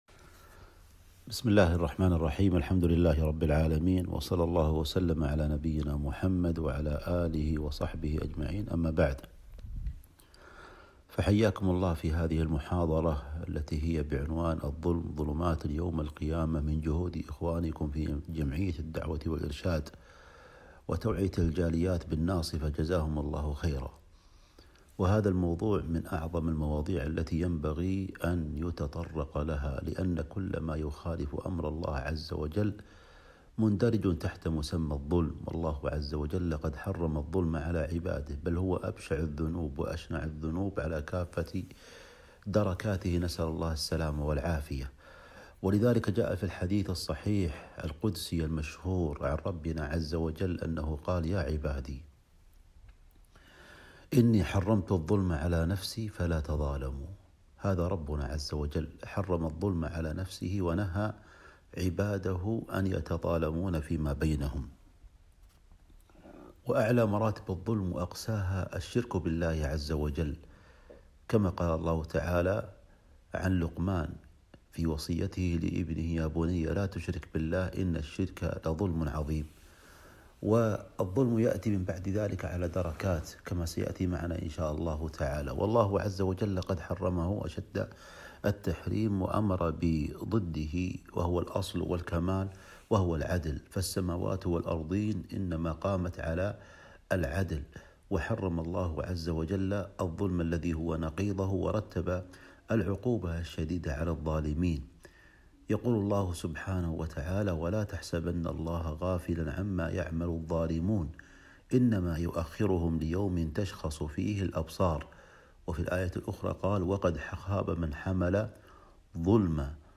محاضرة - شرح حديث (الظلم ظلمات يوم القيامة) 2-8-1442